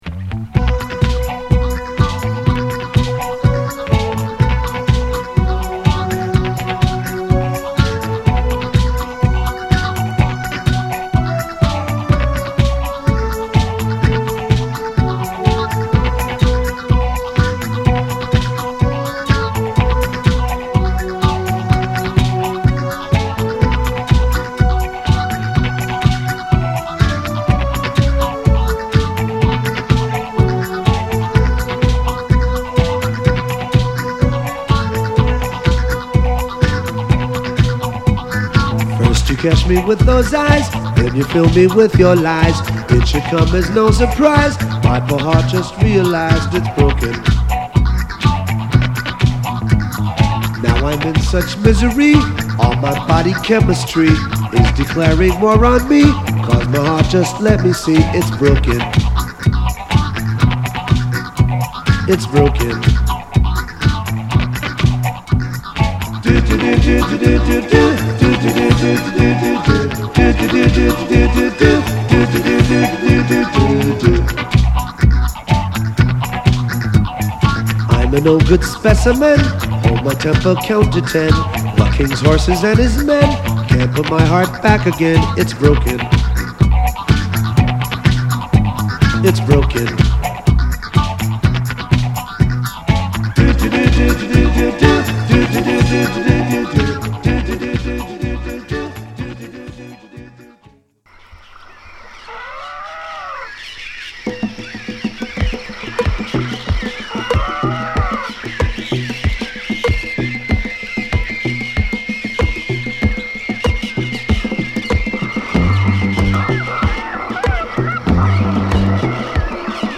コズミックな雰囲気も漂うディスコトラックにイナたいヴォーカルが絡むディープダンスチューン！
密林をさまよう様な錯覚に陥るSEにドロドロとしたベース＆パーカッション、シンセが絡むドープトラックです！